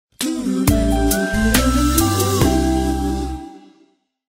Message tone 01.mp3